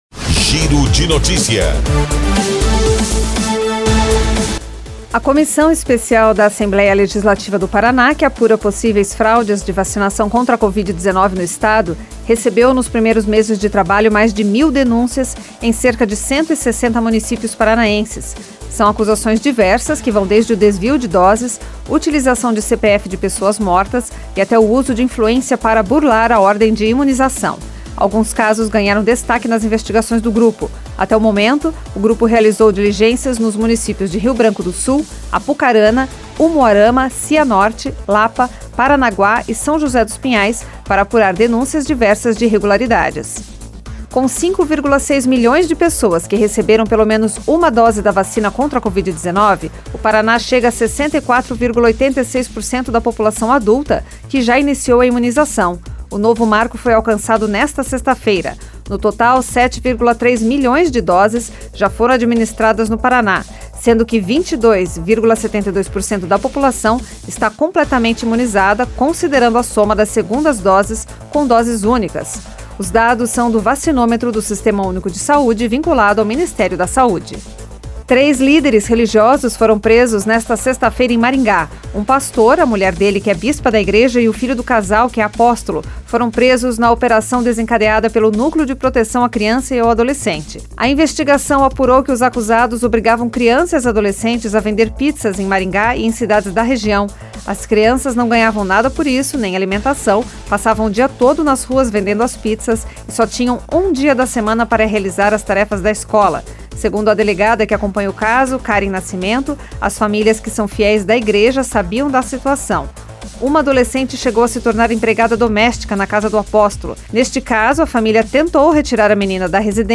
Giro de Notícias Tarde